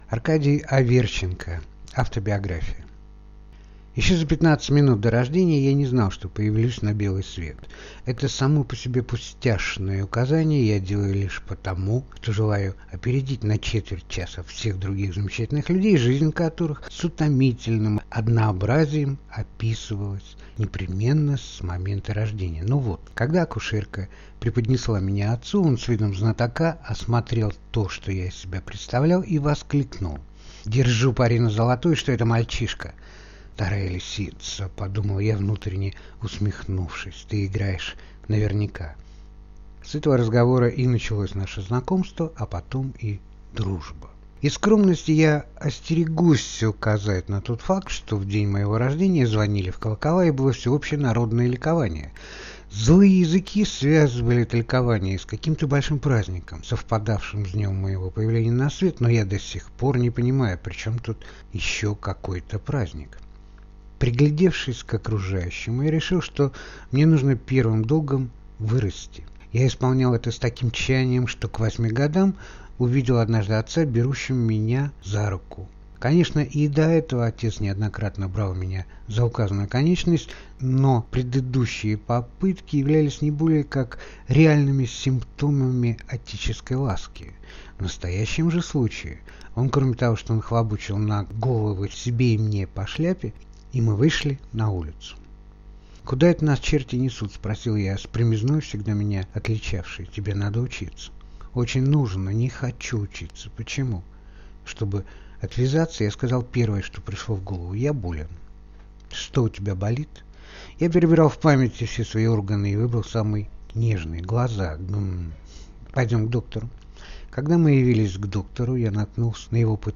Аудиокнига Автобиография | Библиотека аудиокниг